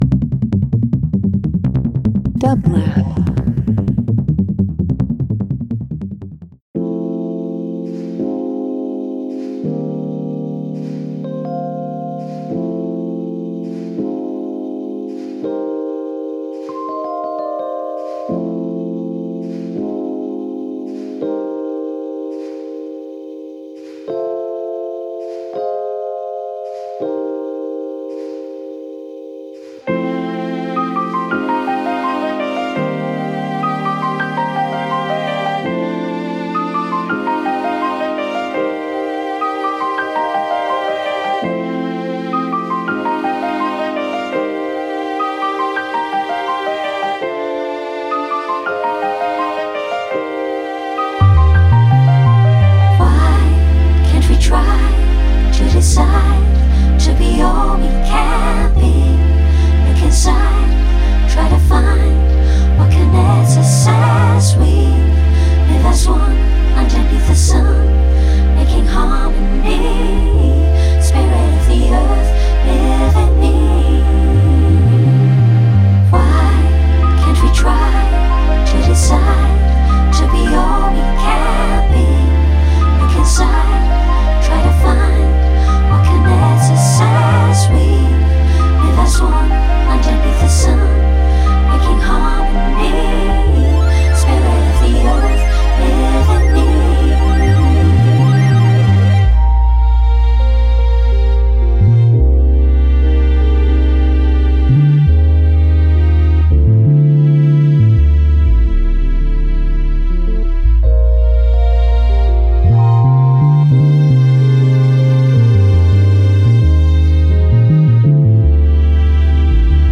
Ambient Electronic Jazz